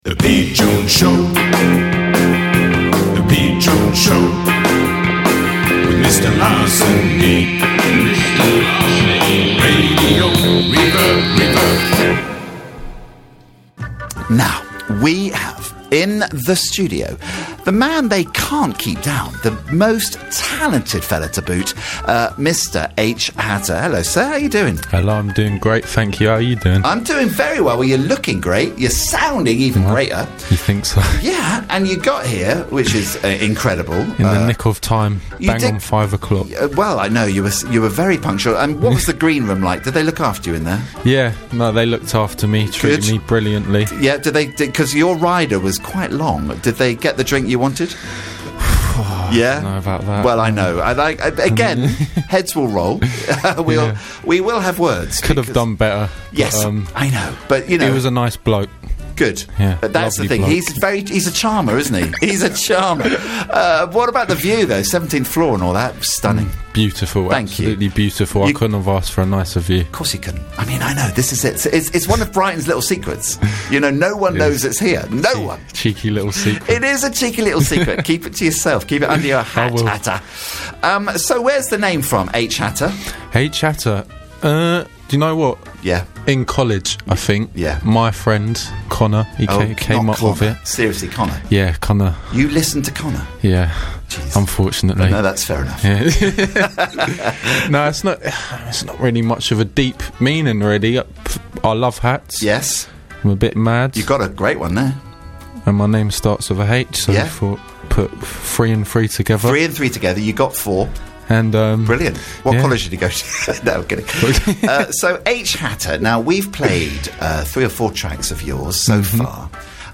Live chat